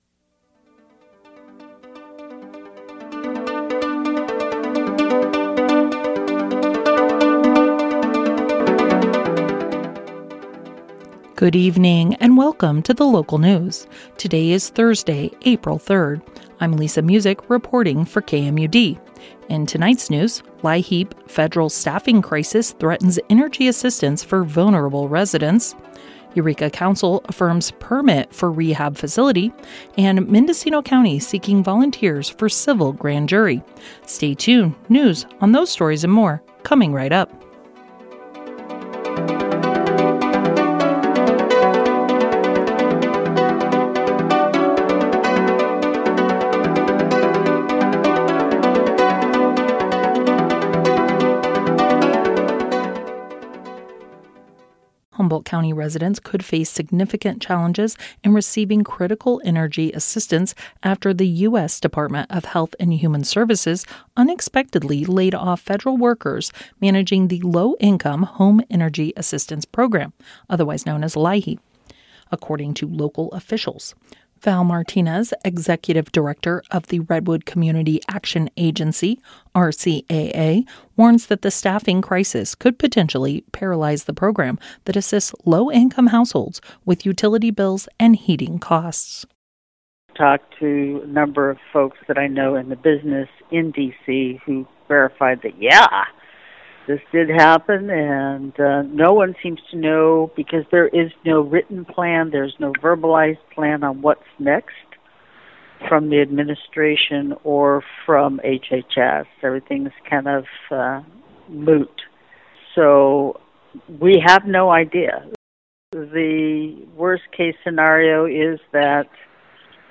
Local News 04 03 25